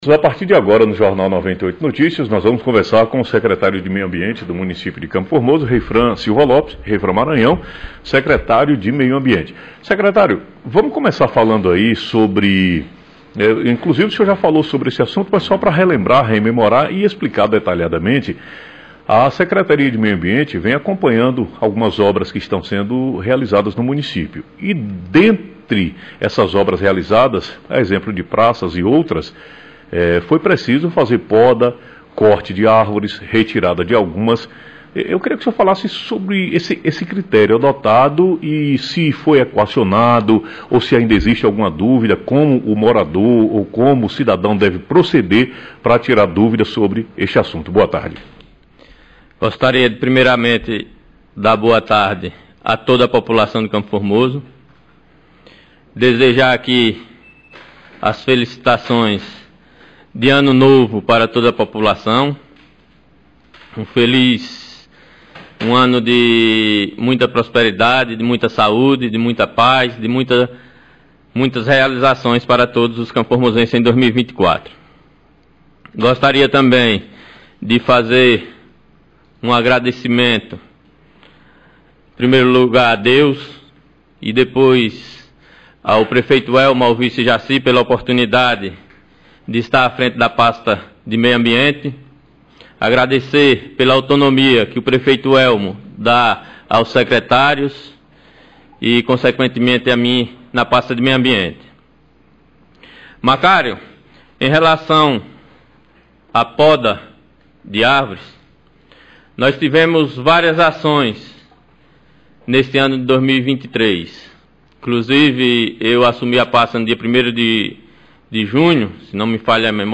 Secretário de meio ambiente de CFormoso, Reifran Maranhão em entrevista ao vivo